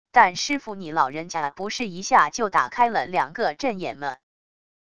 但师父你老人家不是一下就打开了两个阵眼么wav音频生成系统WAV Audio Player